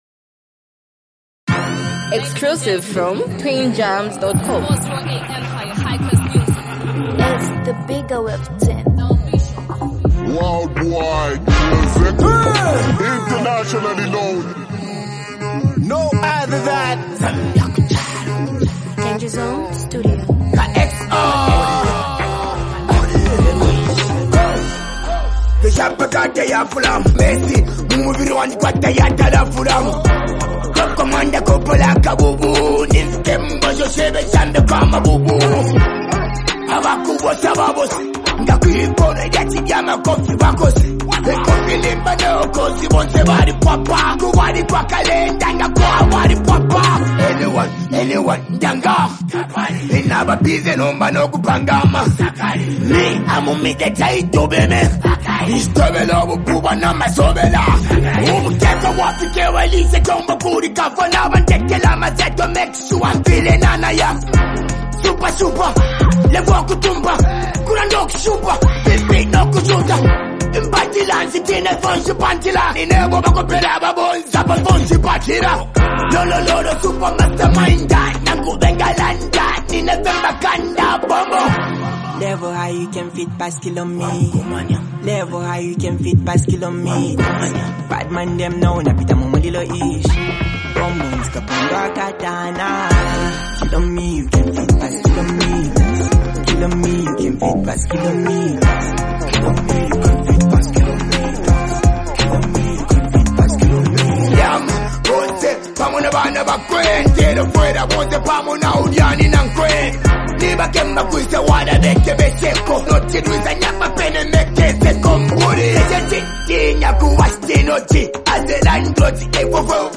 energetic and motivational street anthem